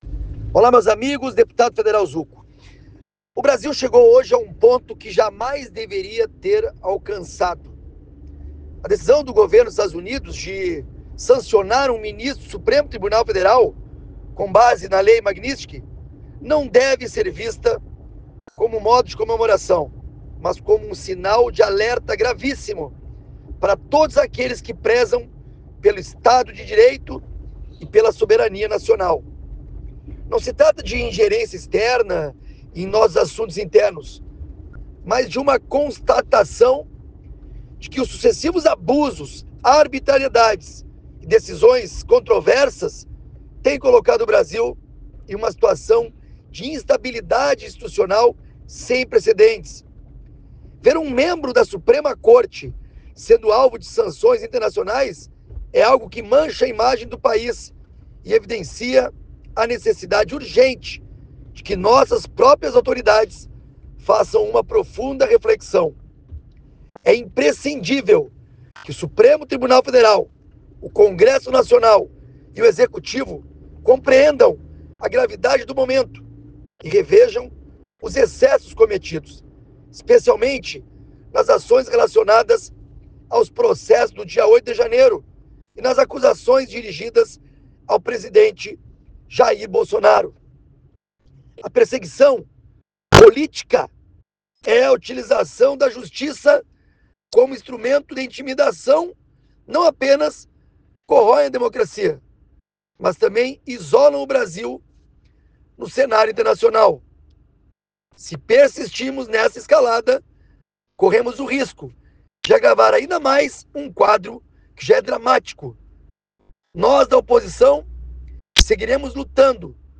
Sonora deputado Zucco sobre sanções ao ministro Alexandre de Moraes